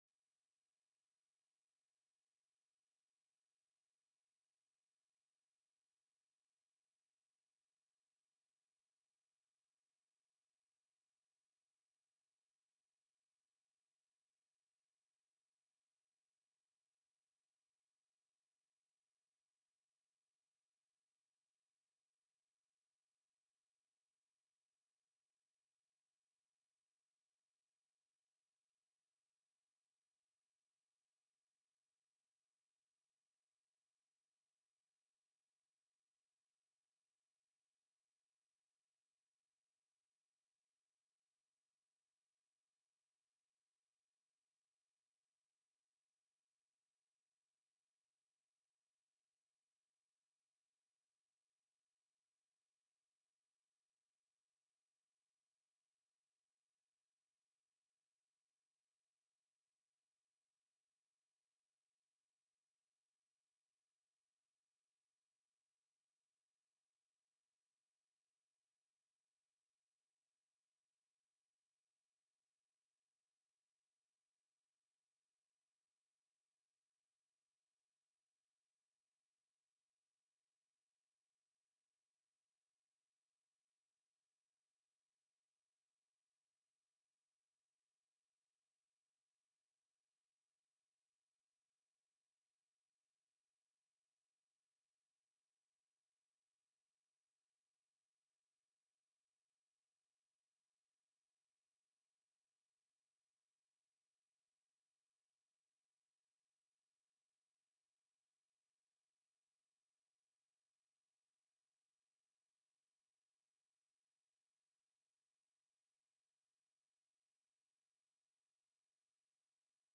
The audio recordings are captured by our records offices as the official record of the meeting and will have more accurate timestamps.
SB 196 BEH. HEALTH CRISIS SURCHARGE & FUND TELECONFERENCED Heard & Held
SB 225 TRUSTS; TRUST PROCEEDINGS; TRUSTEES TELECONFERENCED Heard & Held -- Public Testimony